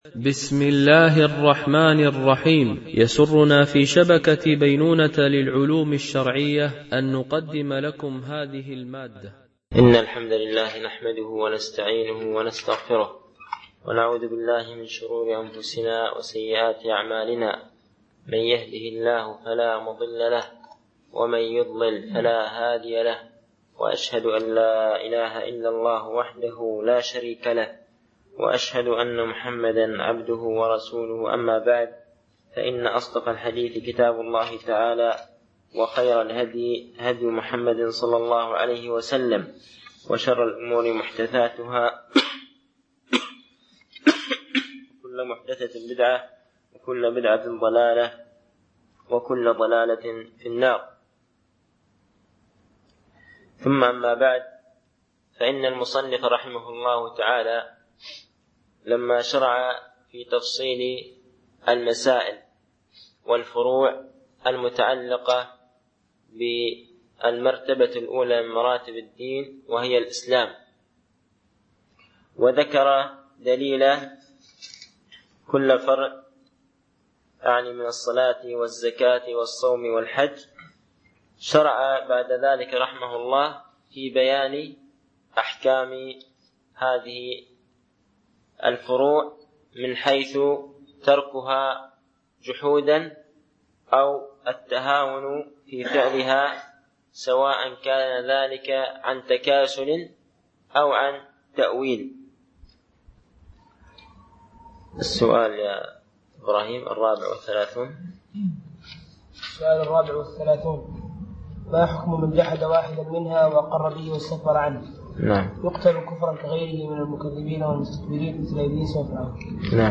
) الألبوم: شبكة بينونة للعلوم الشرعية التتبع: 7 المدة: 45:52 دقائق (10.54 م.بايت) التنسيق: MP3 Mono 22kHz 32Kbps (CBR) ▸ شرح أعلام السنة المنشورة ـ الدرس 06 ( ما دليل شهادة أن محمدا رسول الله صلى الله عليه وسلم ؟...